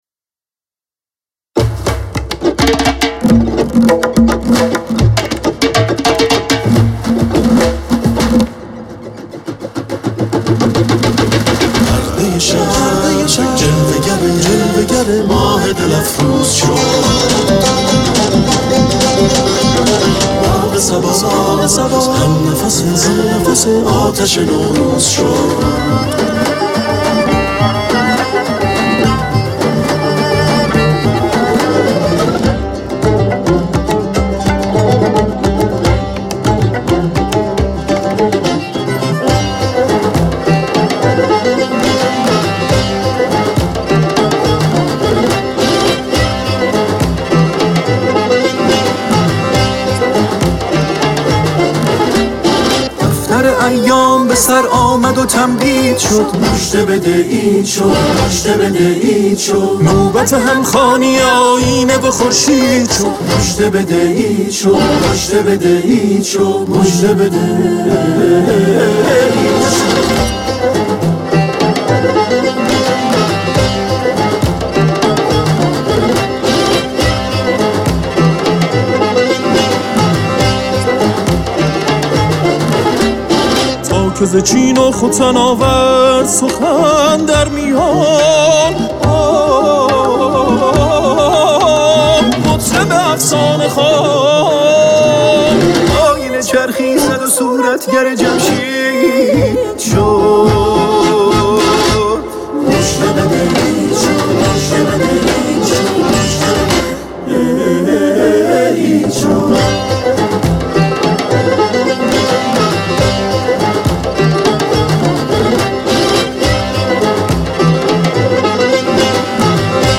کمانچه
عود
دف